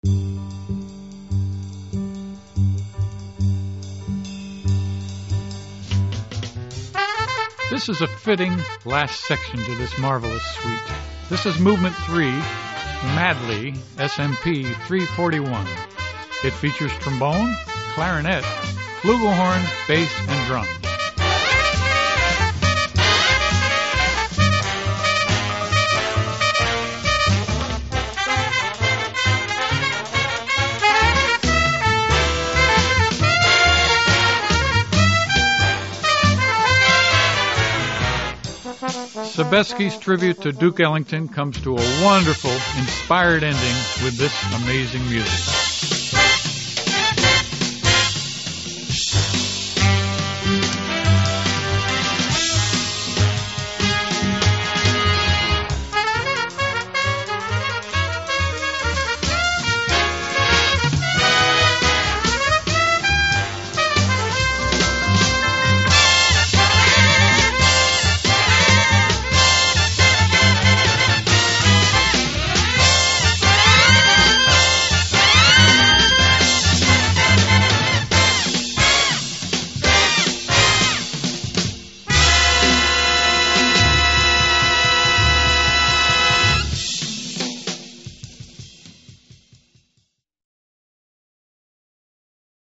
Every chart requires some woodwind doubling.
It features trombone, clarinet, flugelhorn, bass and drums.
Soprano sax doubles required. 5-4-4-3 (on Demo CD 110)